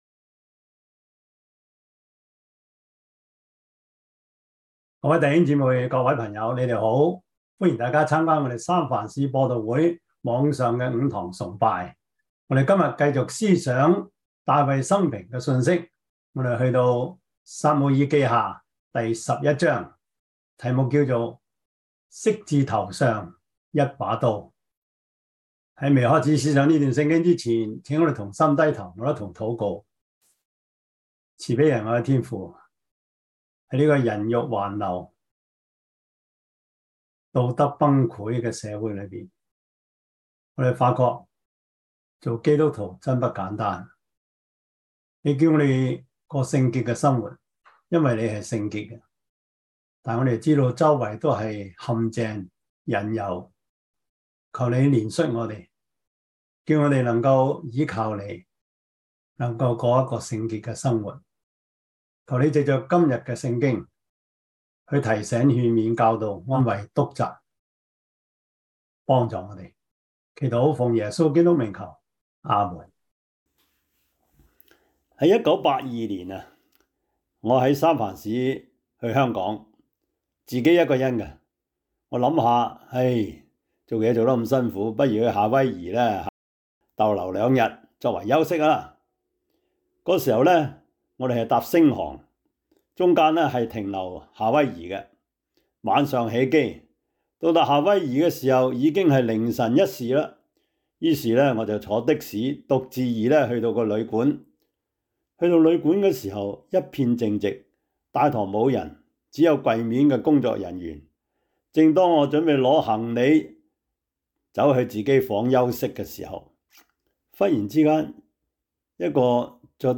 撒母耳記下 11 Service Type: 主日崇拜 撒母耳記下 11 Chinese Union Version